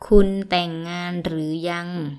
– kunn _ dtaeaeng – ngaan ∨ rueue – yang